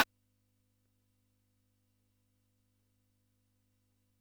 Index of /90_sSampleCDs/300 Drum Machines/Keytek MDP-40
004RIMSHOT.wav